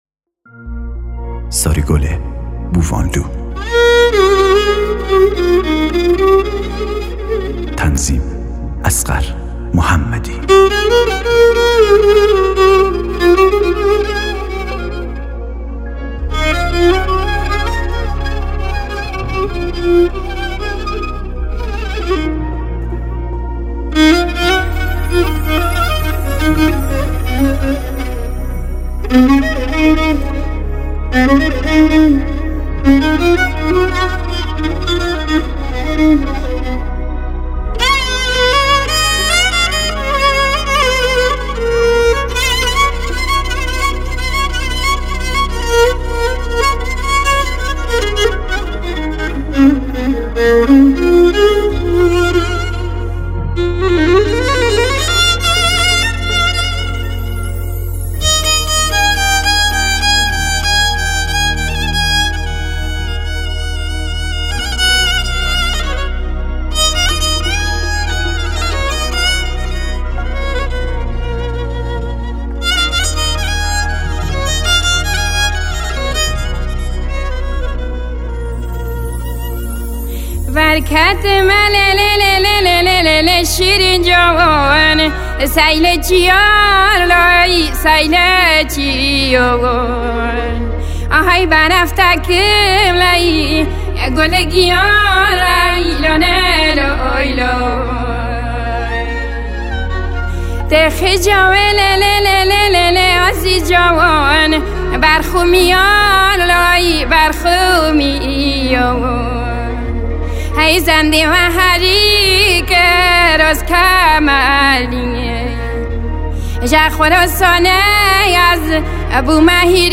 اهنگ کرمانجی